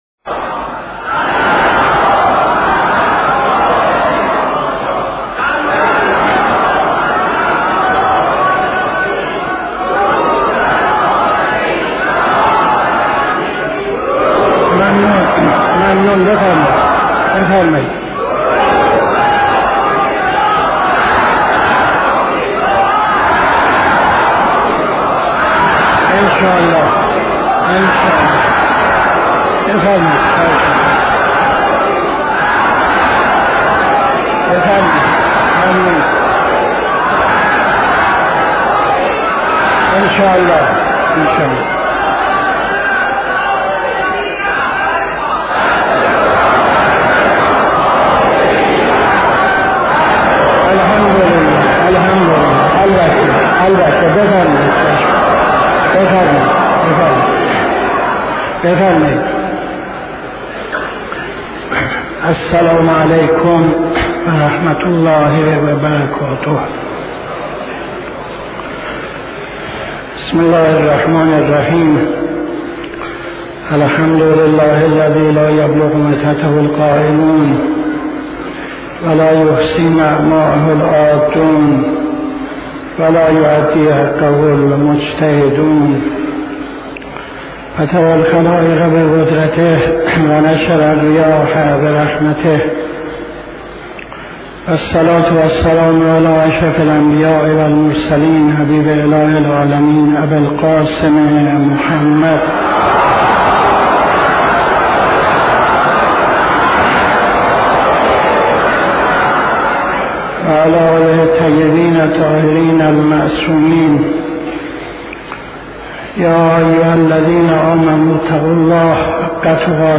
خطبه اول نماز جمعه 10-02-78